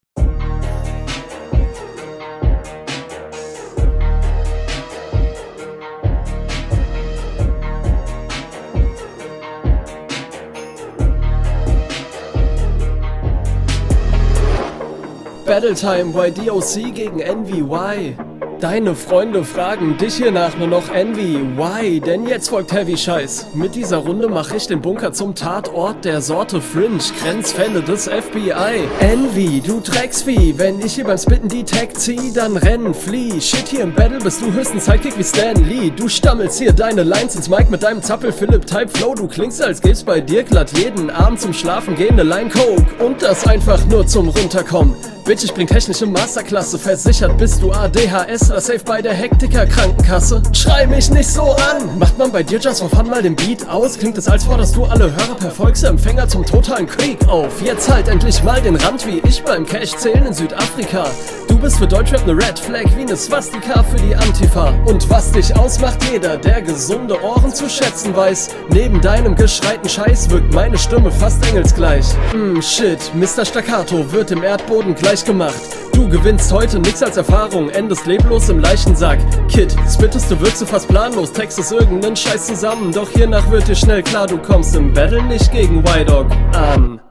Beat geht anders hart, nice! Flow gefällt mir auch.